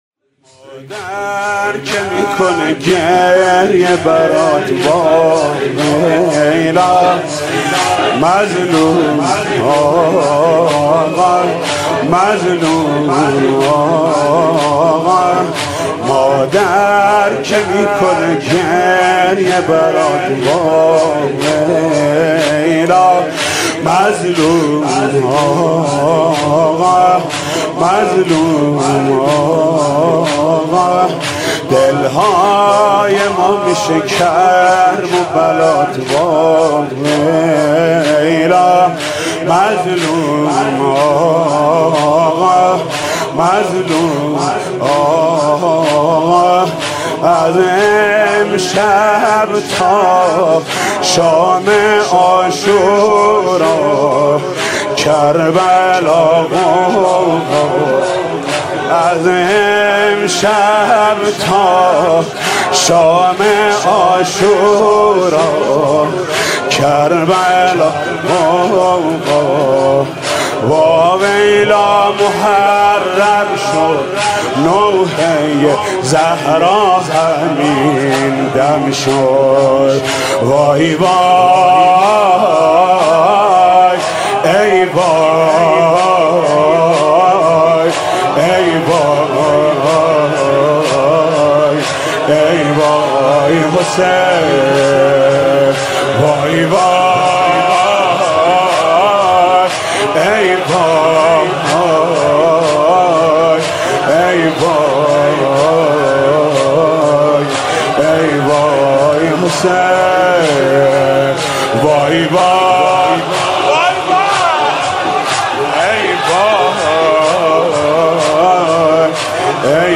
شب اول محرم الحرام 1394 | هیات رایه العباس | حاج محمود کریمی
مادر که میکنه گریه برات واویلا | زمینه | ورود به محرم